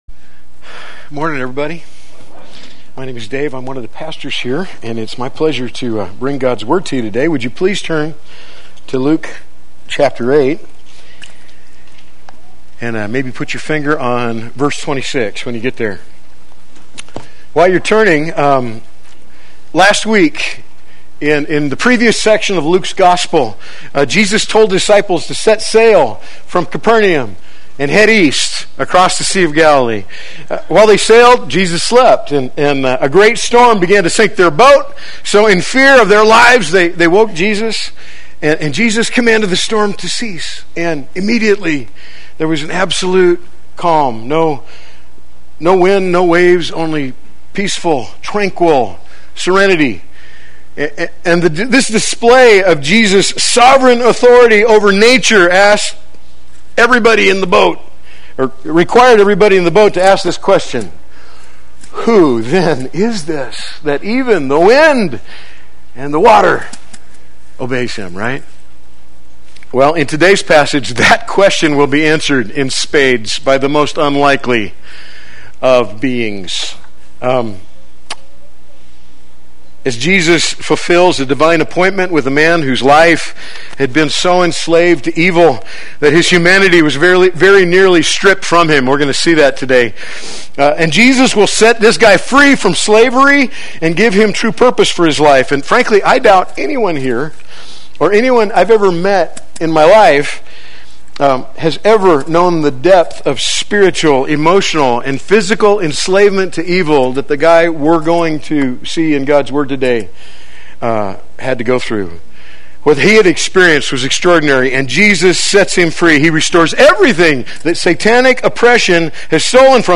Play Sermon Get HCF Teaching Automatically.
Mighty to Save Sunday Worship